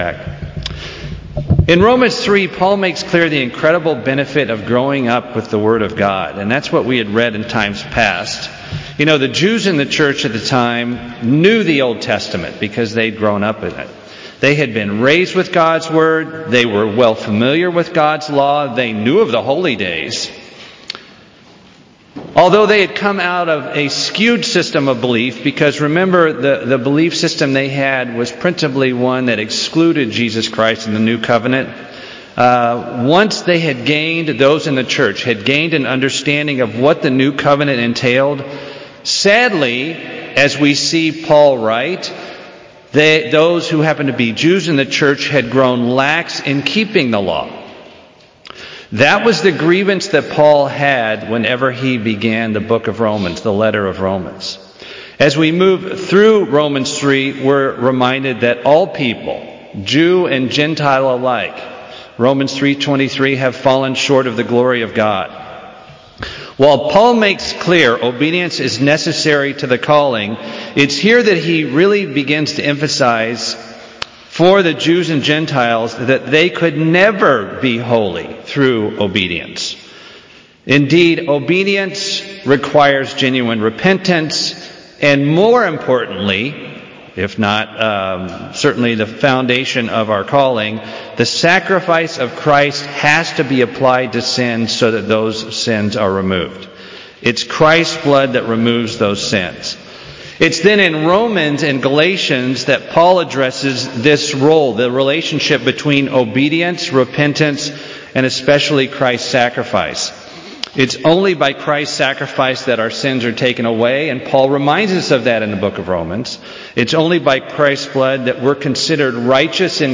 UCG Sermon Romans Abraham justification righteousness Transcript This transcript was generated by AI and may contain errors.